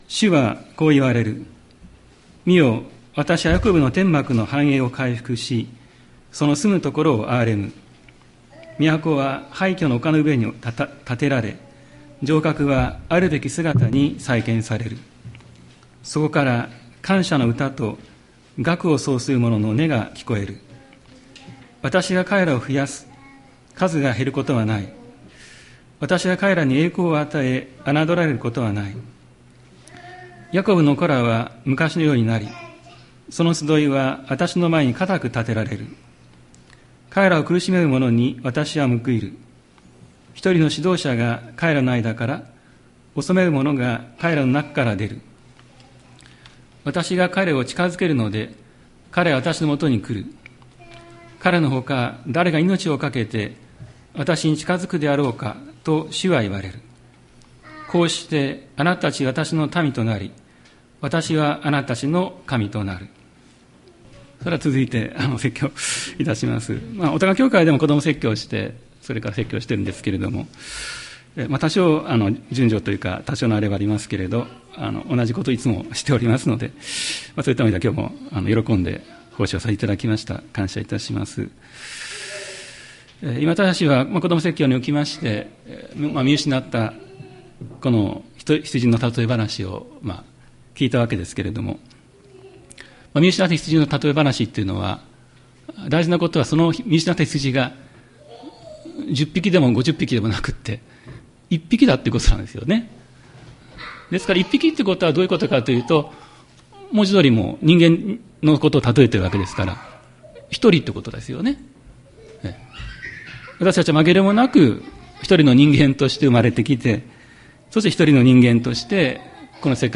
2023年07月30日朝の礼拝「約束と約束をつなぐお方」吹田市千里山のキリスト教会
千里山教会 2023年07月30日の礼拝メッセージ。